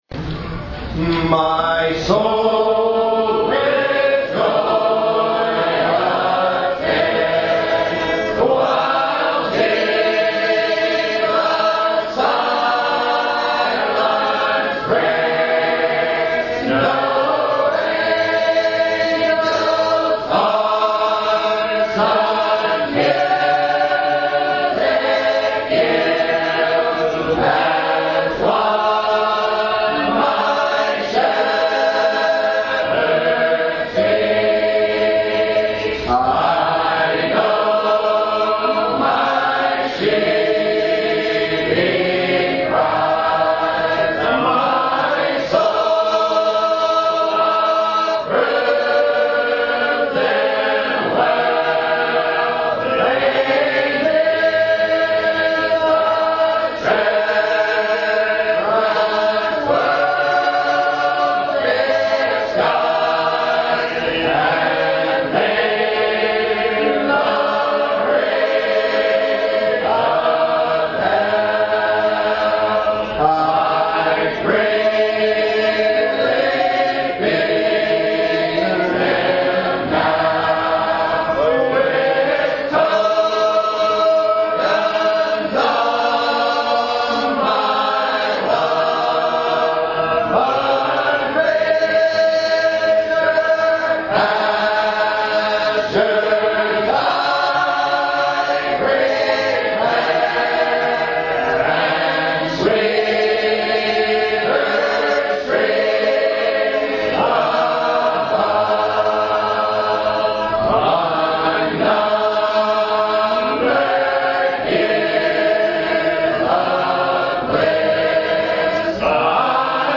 MP3's of A Cappella Singing scripture songs of praise with title beginning with letter M
a-capella-hymn-my-soul-with-joy-attend.mp3